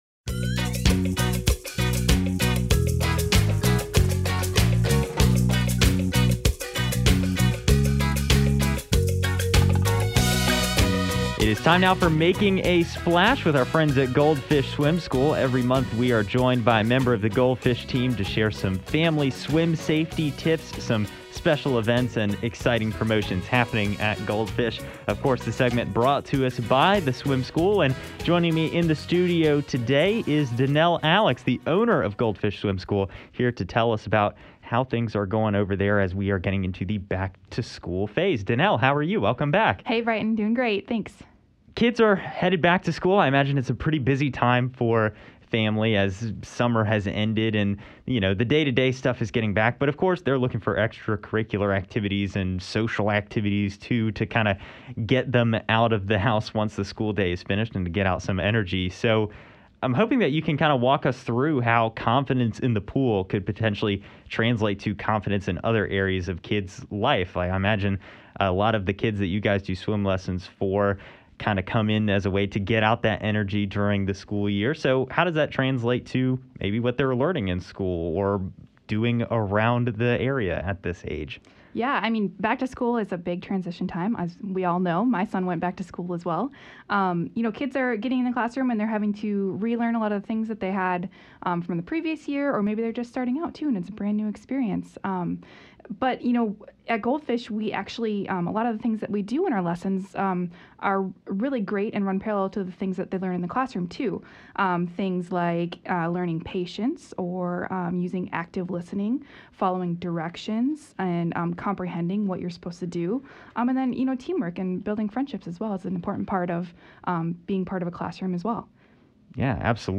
There’s fun promotions and giveaways currently going on at Goldfish too, from a full prize pack to free trial memberships, so make sure to listen all the way to the end of the interview!